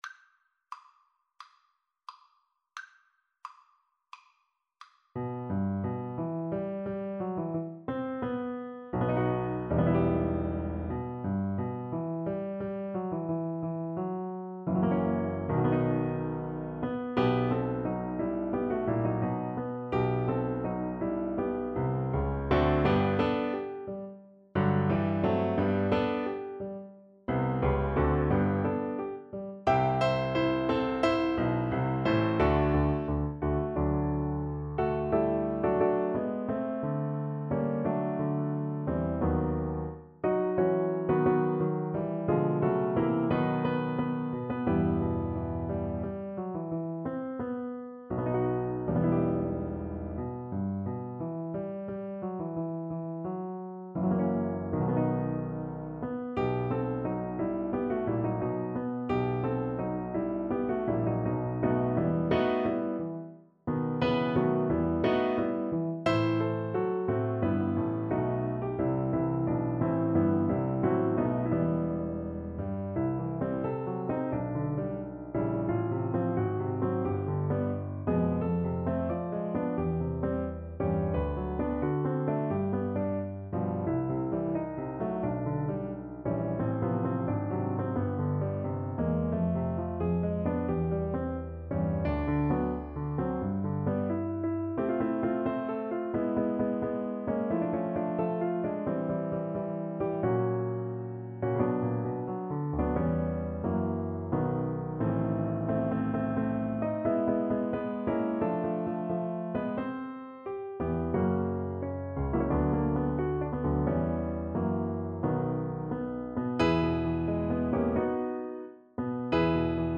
=88 Nicht schnell =100
Classical (View more Classical Cello Music)